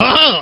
Line of Krunch in Diddy Kong Racing.